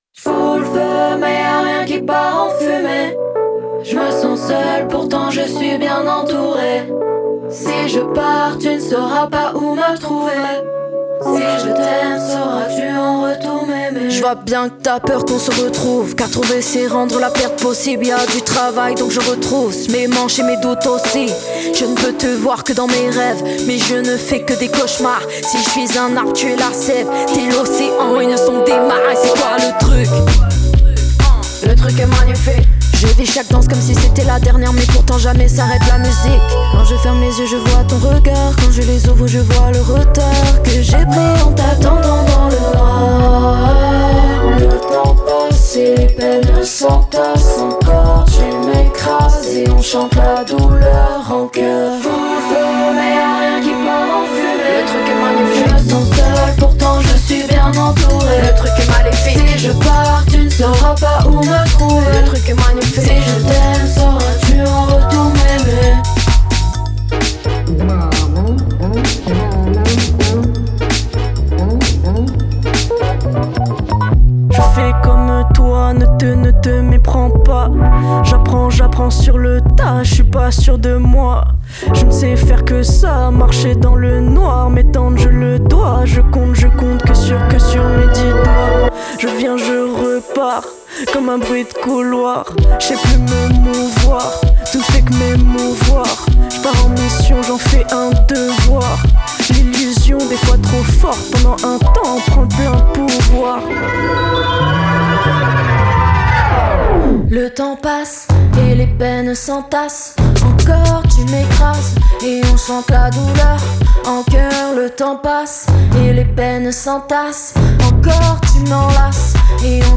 pièce aux rythmes endiablés
rap
rap français